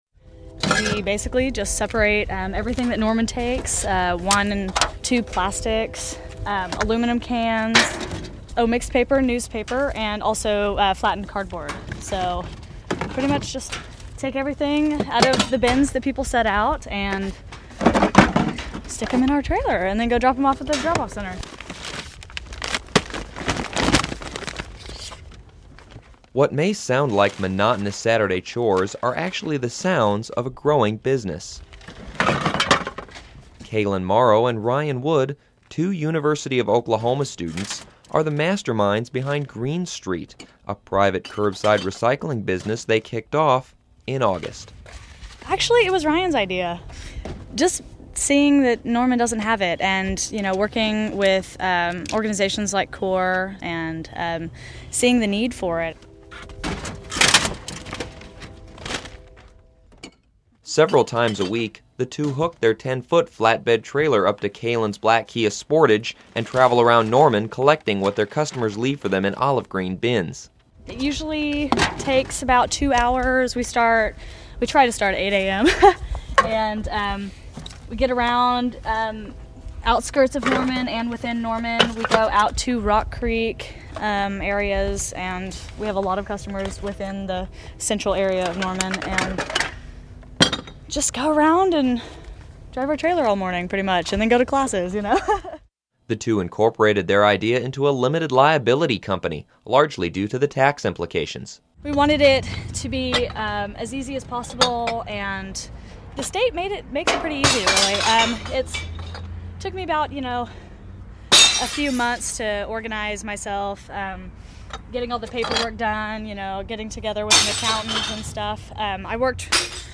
Fourth Place Radio I – Features - Hearst Journalism Awards Program